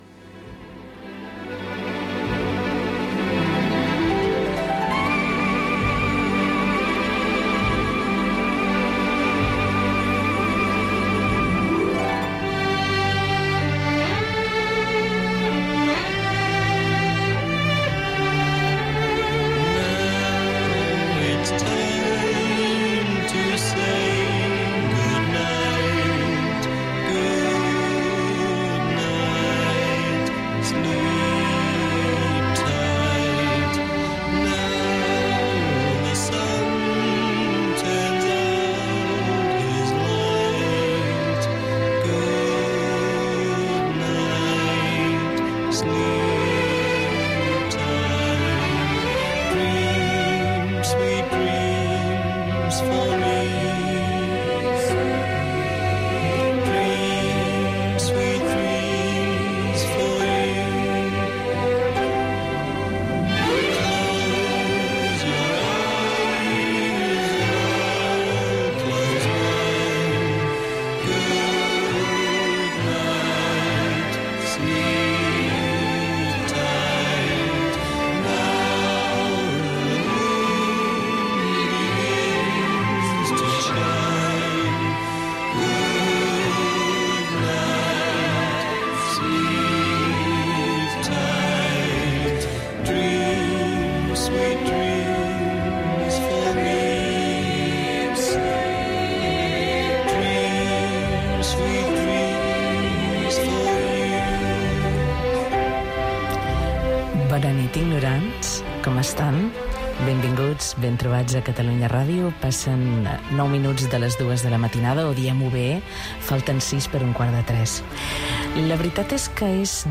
trucada telefònica